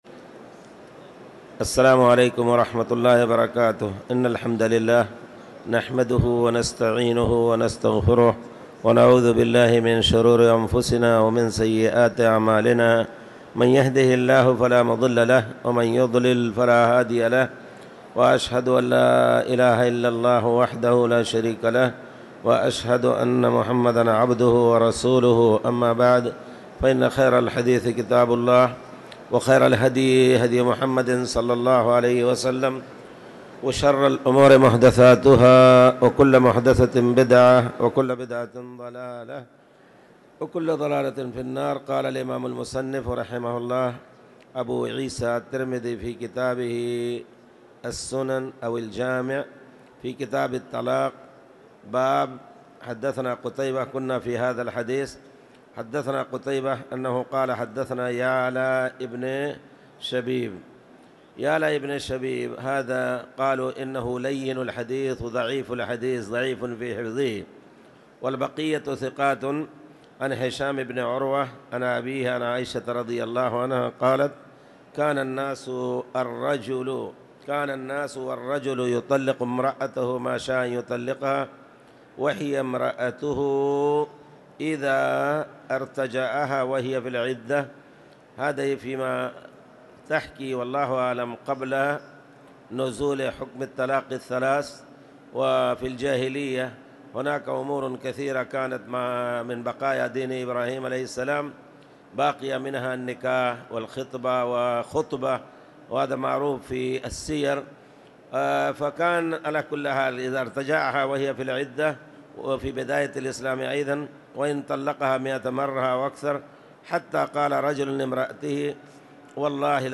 تاريخ النشر ١٠ صفر ١٤٣٨ هـ المكان: المسجد الحرام الشيخ